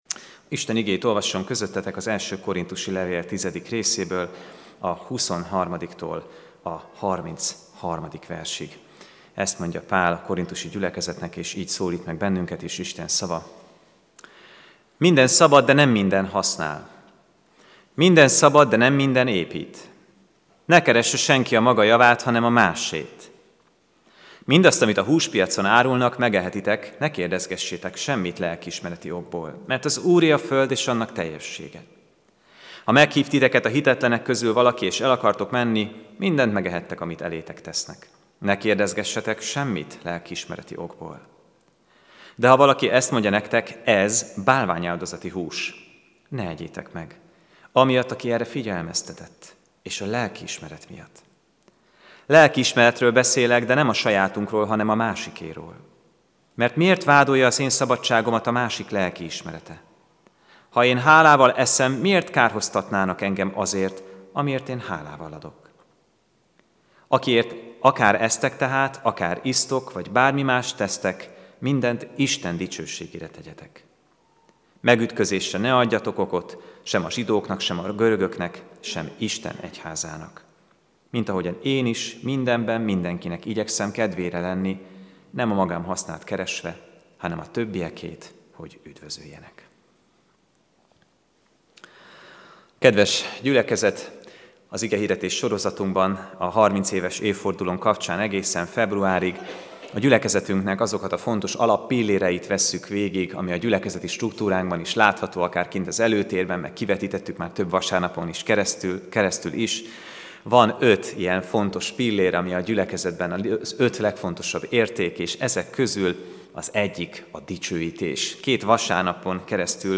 AZ IGEHIRDETÉS LETÖLTÉSE PDF FÁJLKÉNT AZ IGEHIRDETÉS MEGHALLGATÁSA